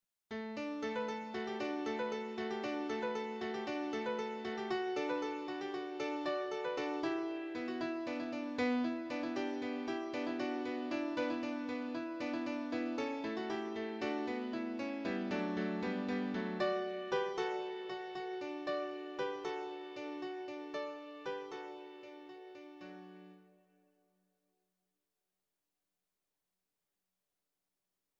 bustling perpetuum mobile song for women's voices
Type: Electronically Generated Source